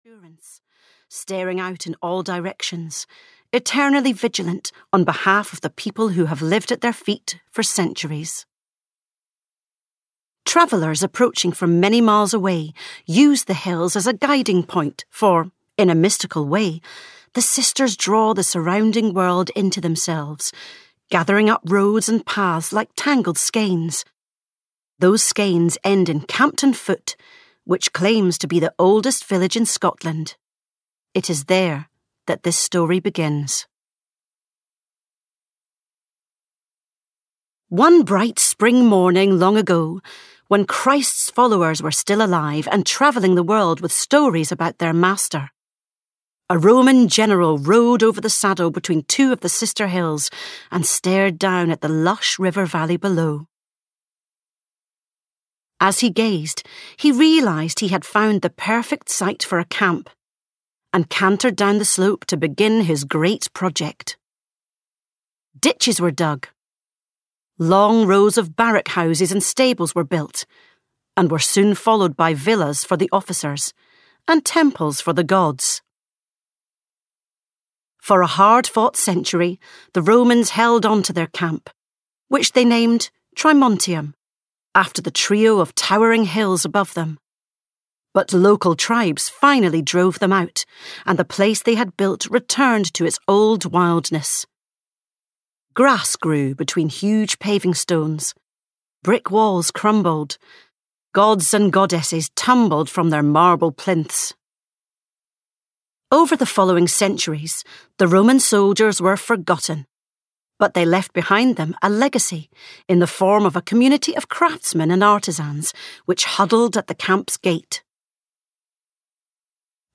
A Bridge in Time (EN) audiokniha
Ukázka z knihy